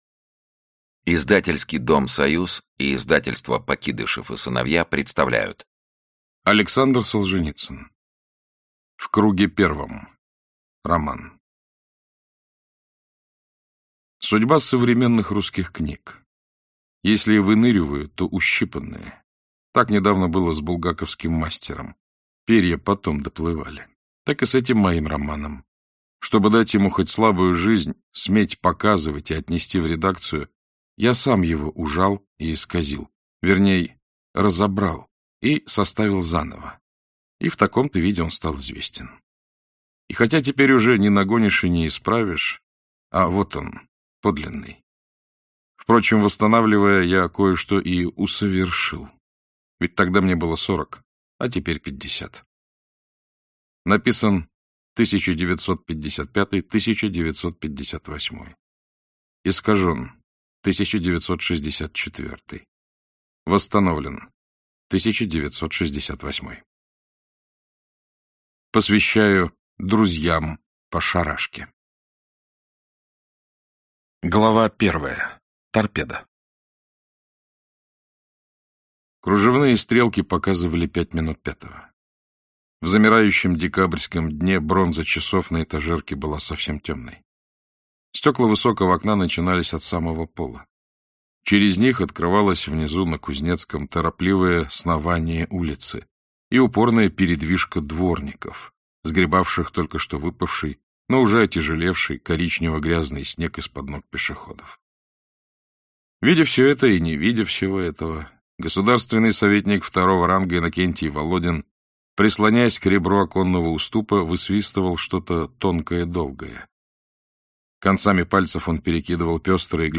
Аудиокнига В круге первом - купить, скачать и слушать онлайн | КнигоПоиск